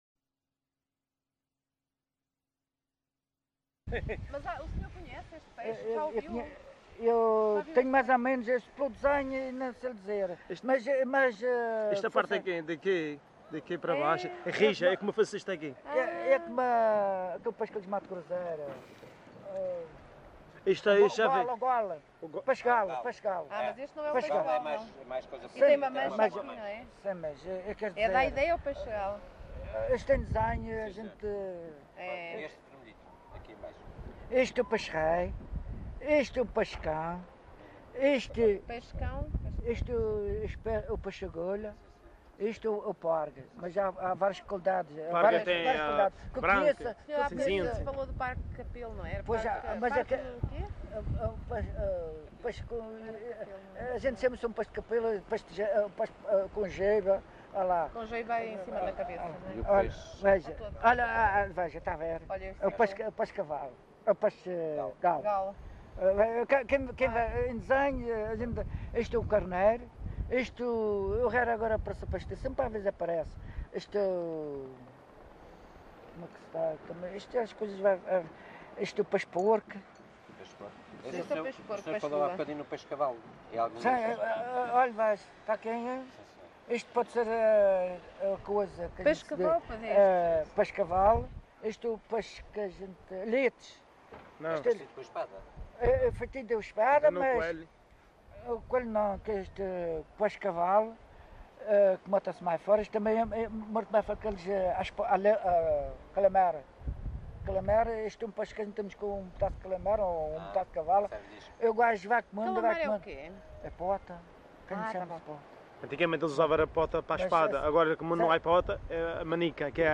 LocalidadeCâmara de Lobos (Câmara de Lobos, Funchal)